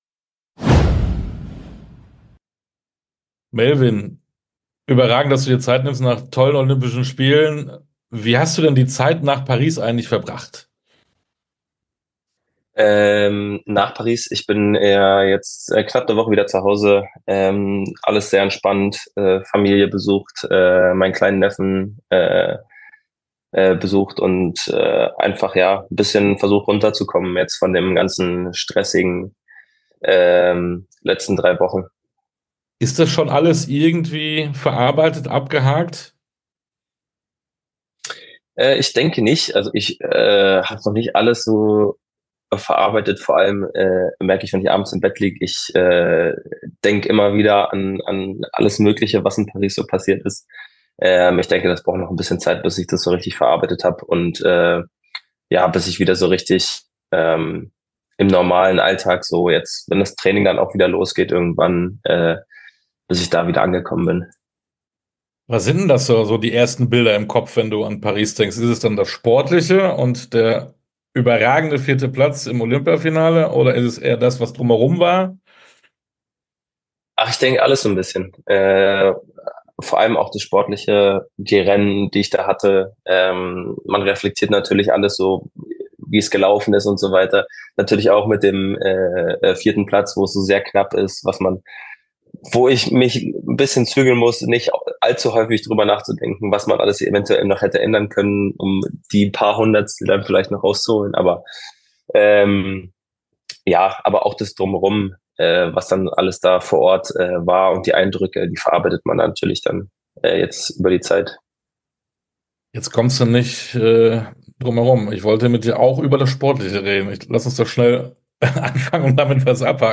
Interviews in voller Länge Podcast